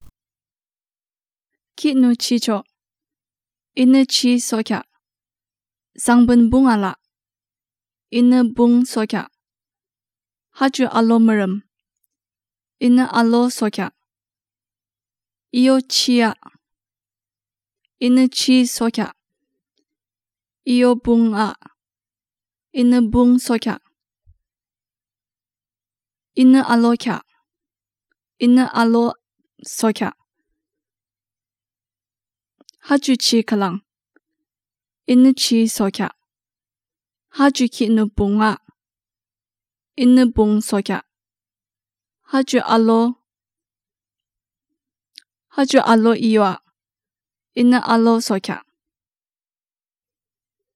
Elicitation of words for minimal pair in Yimkhiung